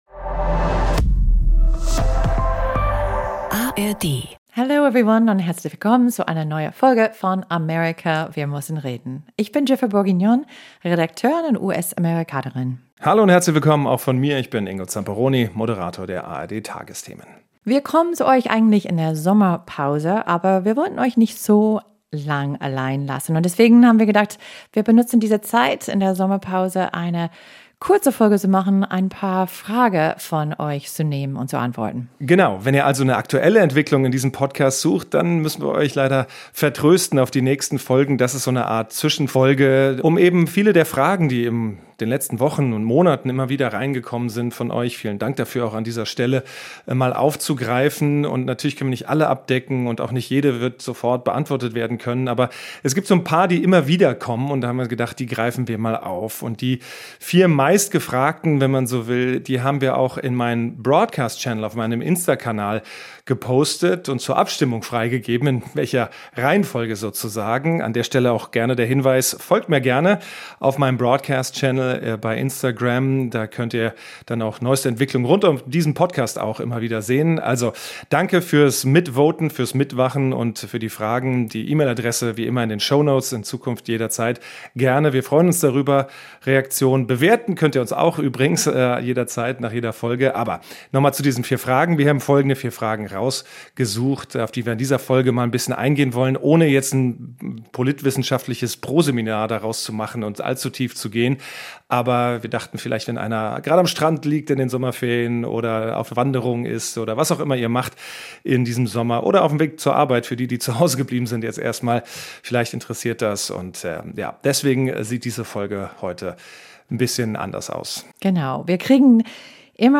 Das Ehepaar diskutiert Vor- und Nachteile des Systems und beantwortet die häufigsten Fragen zur Wahl des Präsidenten am 5. November.